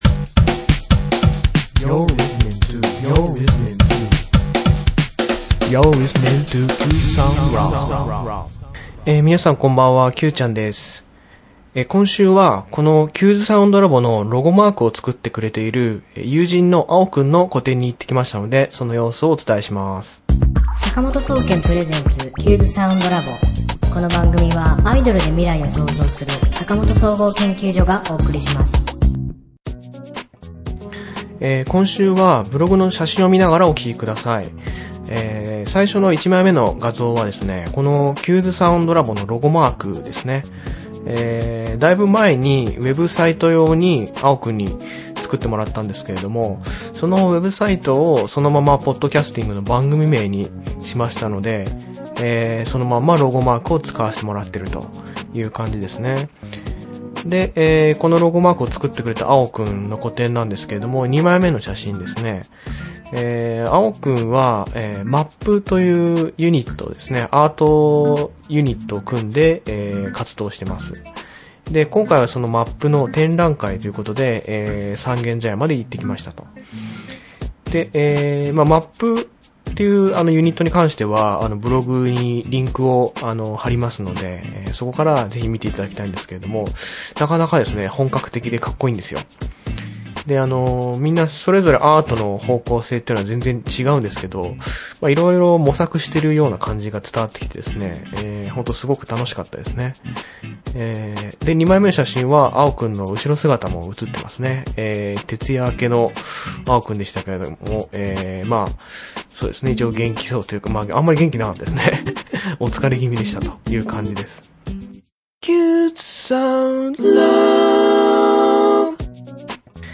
今週の挿入歌『君とずっと暮らしたい』